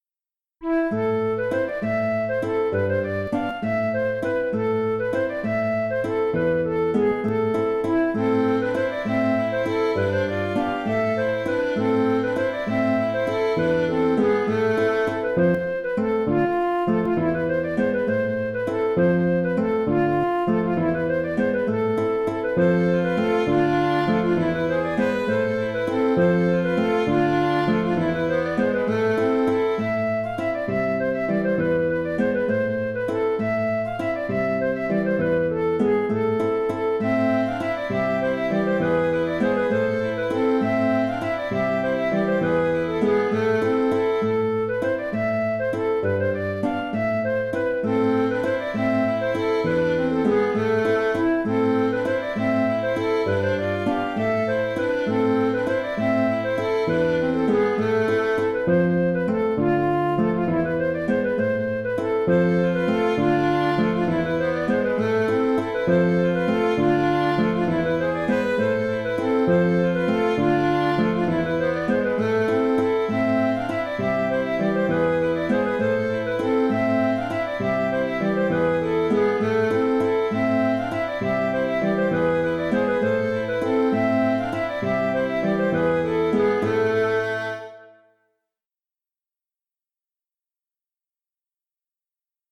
Bourrée 3 temps / Crouzade
J’ai composé cette mélodie en trois parties, mais si vous souhaitez avoir un nombre pair de parties, vous pouvez aussi doubler la première partie.